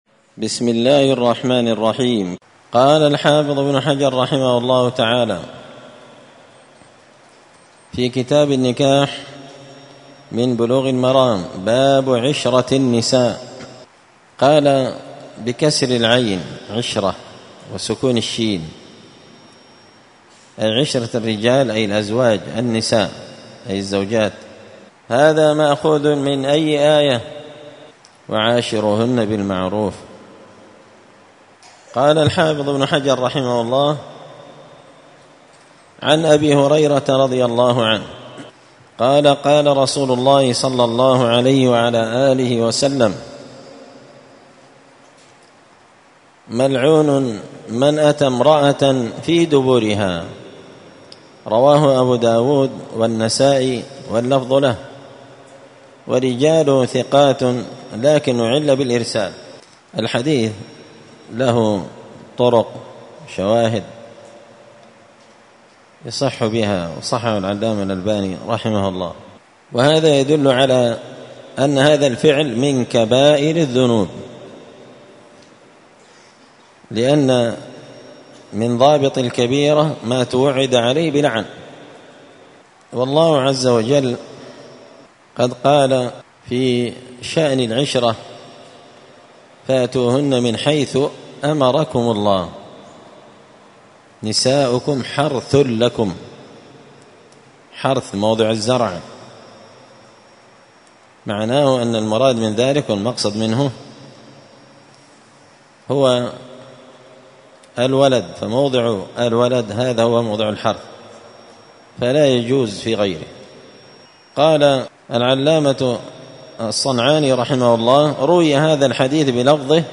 *الدرس 20 تابع أحكام النكاح {باب عشرة النساء}*
مسجد الفرقان_قشن_المهرة_اليمن